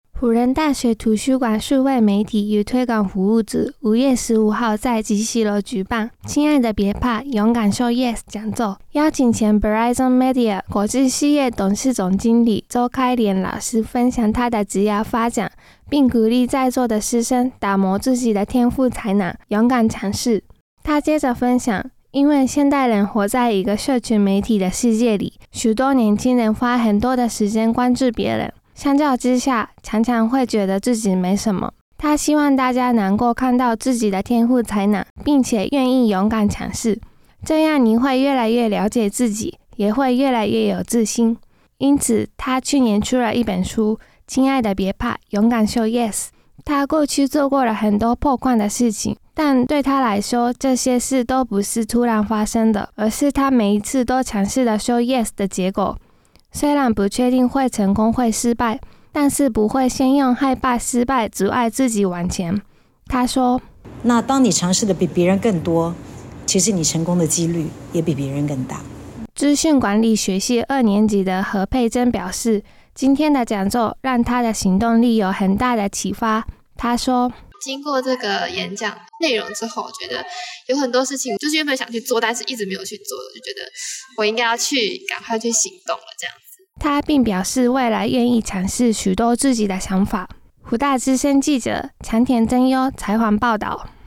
採訪報導